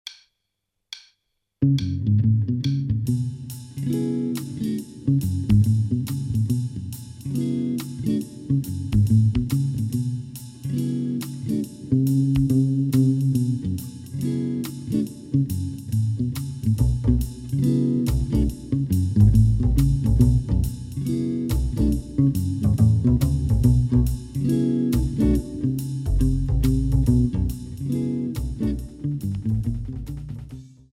Jazz Audios